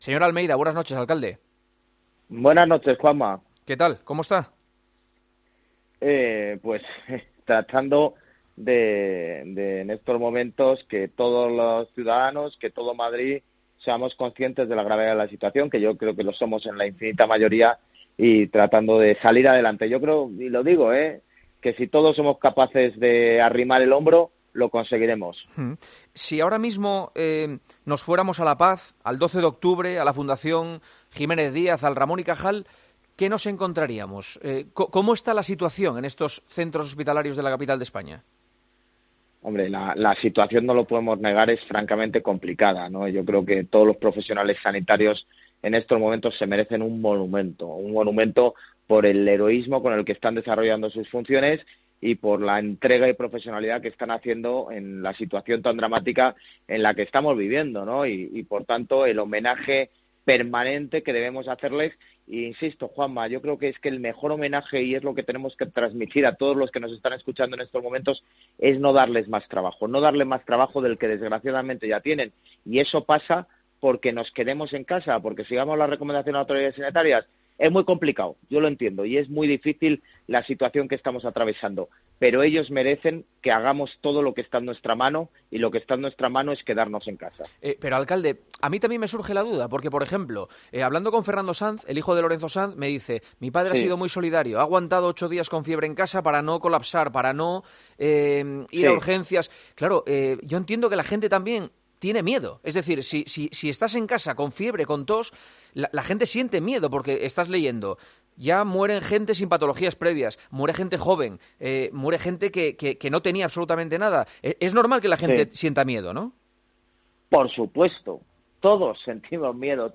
Entrevista al alcalde de Madrid, José Luis Martínez Almeida, para hablar sobre la gran crisis que sufre la capital por el coronavirus.